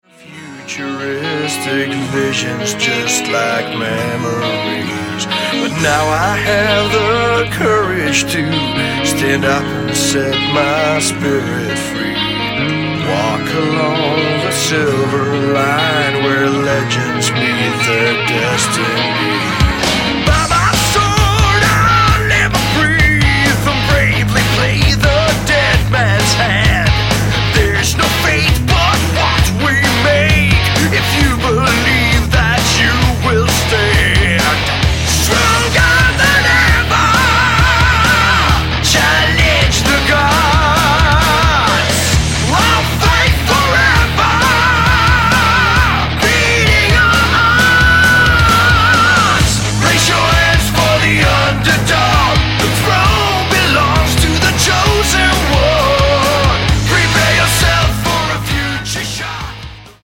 Vocals and bass
Guitars
Drums